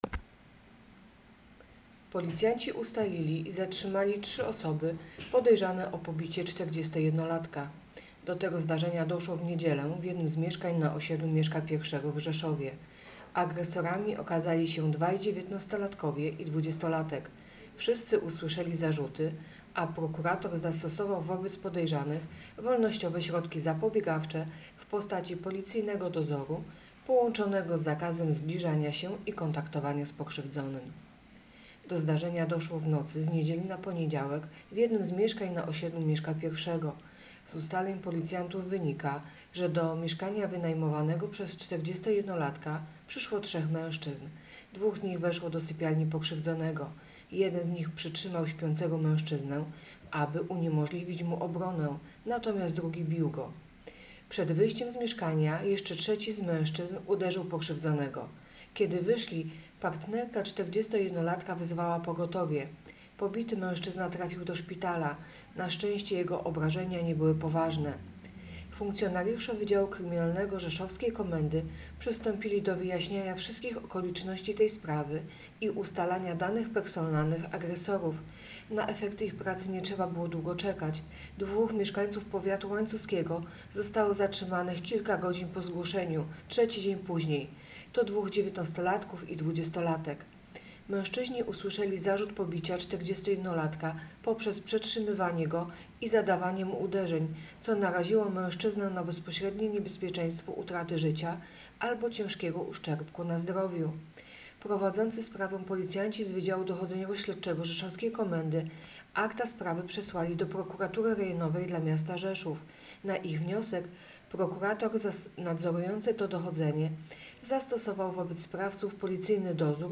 Opis nagrania: Nagranie głosowe informacji pt. Zarzuty dla trzech mężczyzn podejrzanych o pobicie 41-latka.